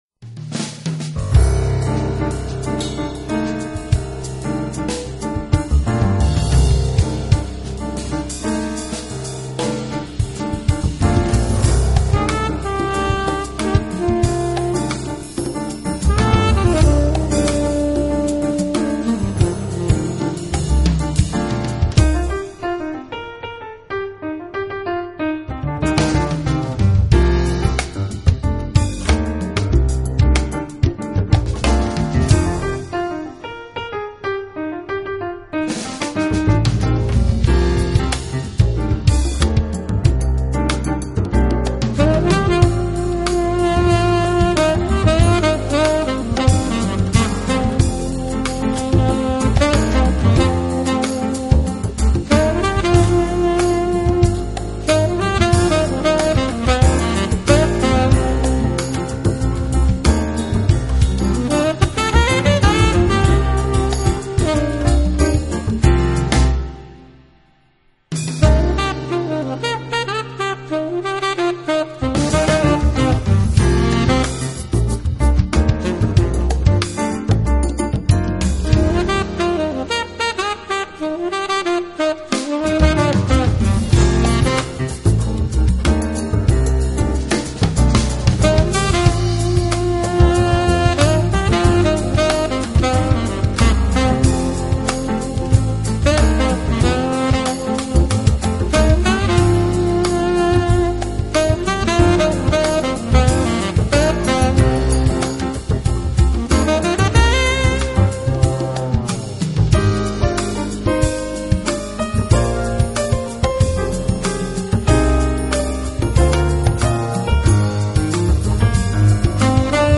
【爵士钢琴】